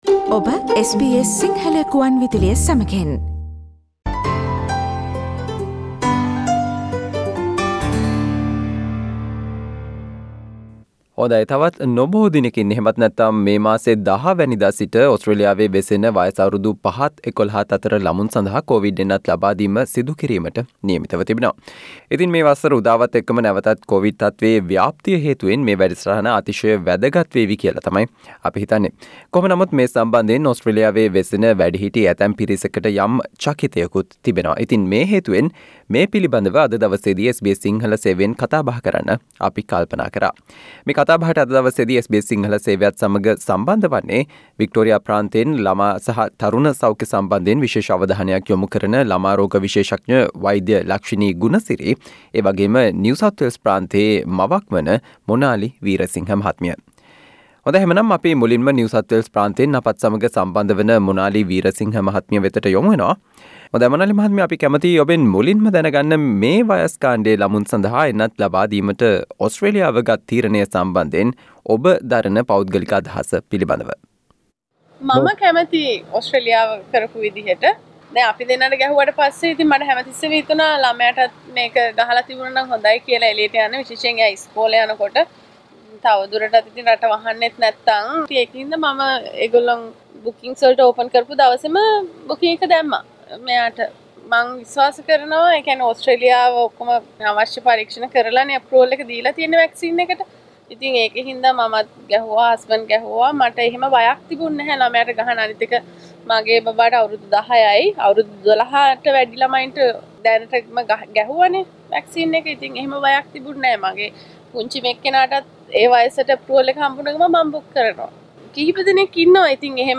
ඕස්ට්‍රේලියාවේ වයස අවුරුදු 5ත් 11ත් අතර වයස් කාණ්ඩයේ ළමුන් සඳහා කෝවිඩ් එන්නත් ලබාදීම සම්බන්ධයෙන් ඕස්ට්‍රේලියාවේ ශ්‍රී ලංකික ප්‍රජාවේ අදහස් සහ එම එන්නෙතෙහි පවතින ආරක්‍ෂිත බව පිළිබඳව SBS සිංහල සේවය සිදු කල සාකච්චාවට සවන් දෙන්න